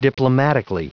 Prononciation du mot : diplomatically
diplomatically.wav